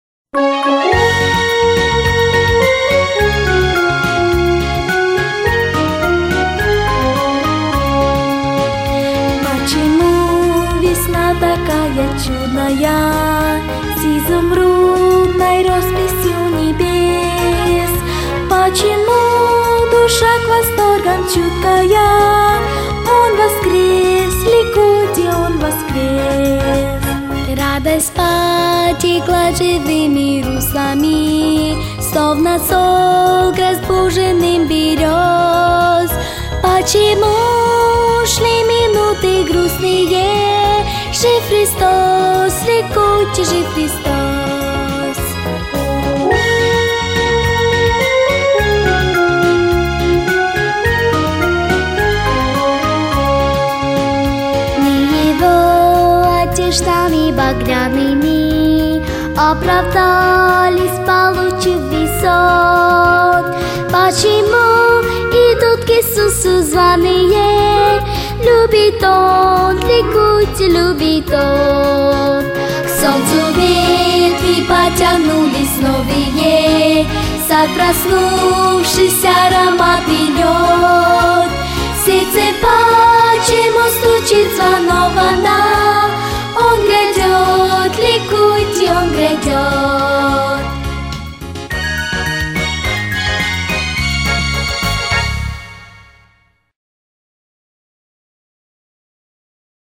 • Категория: Детские песни
христианские песни